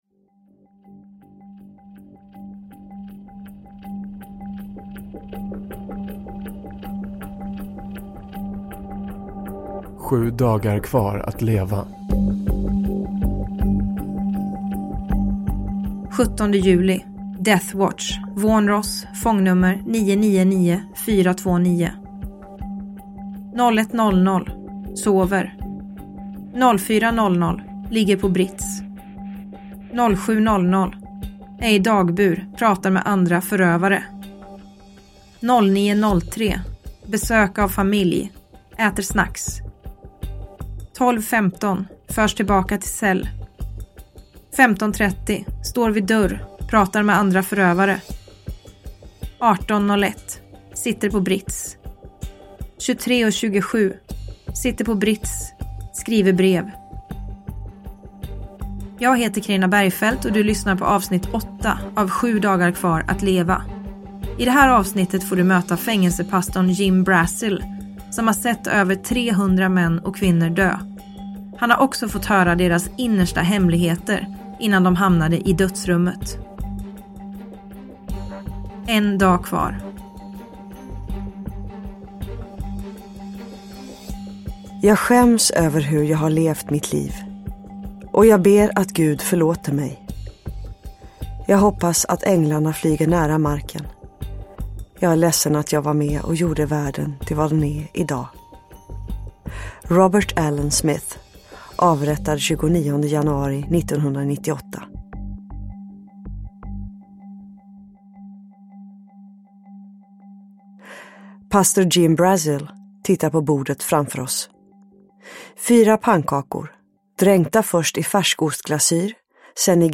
Bergfeldts Amerika. S1A8, Sju dagar kvar att leva – Ljudbok – Laddas ner